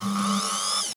SERVO SE13.wav